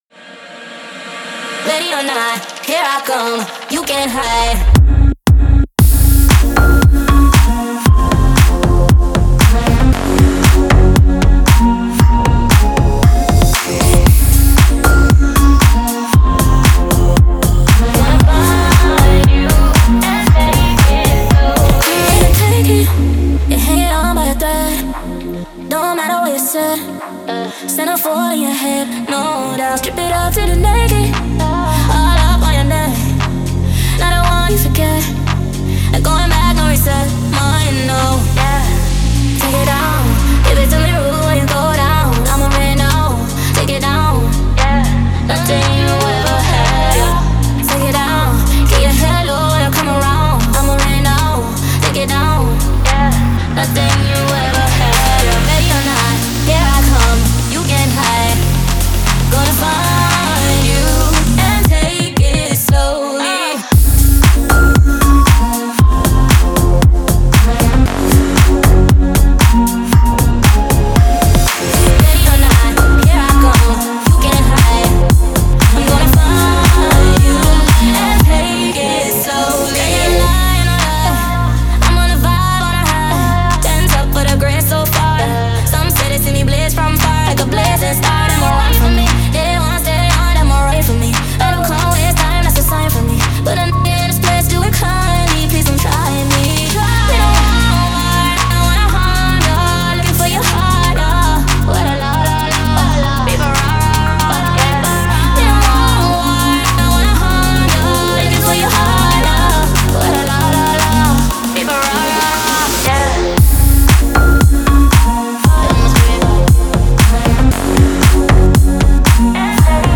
это энергичная трек в жанре танцевальной электронной музыки